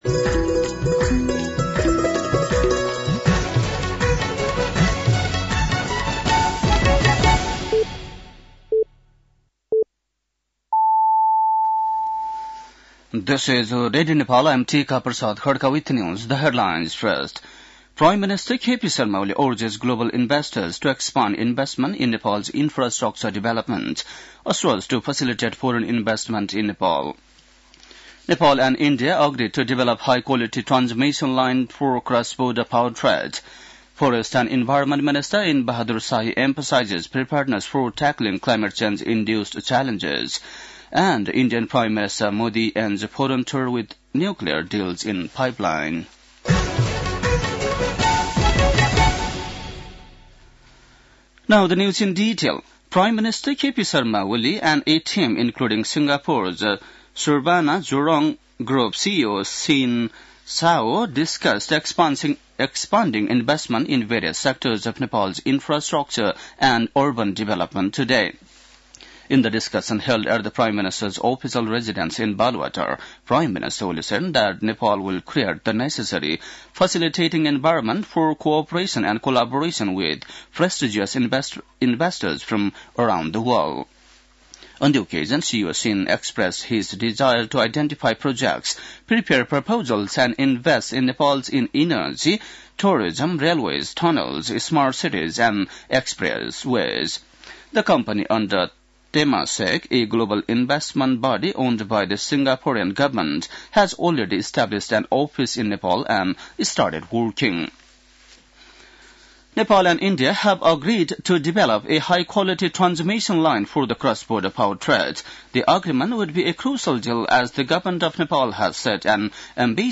बेलुकी ८ बजेको अङ्ग्रेजी समाचार : ३ फागुन , २०८१